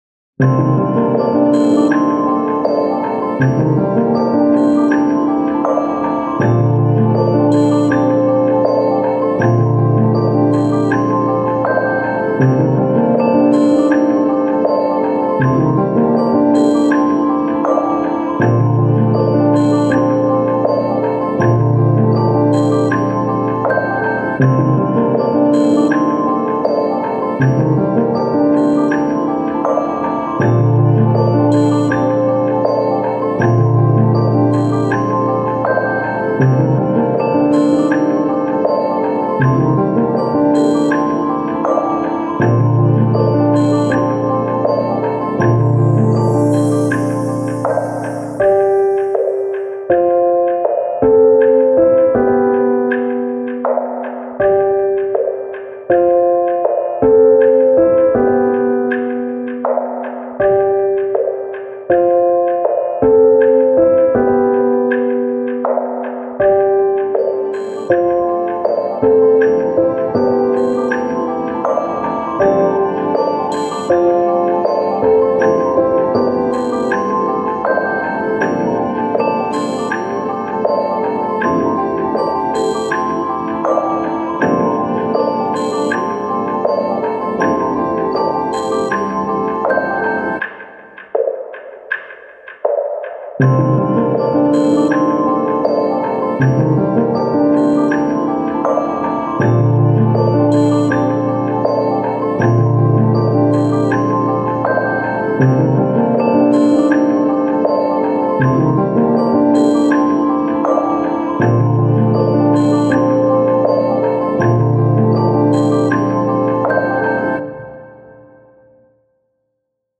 イージーリスニング
アンビエント
ピアノ
シンセ
クール
暗い
悲しい